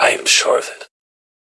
vox-cloned-data
Text-to-Speech
more clones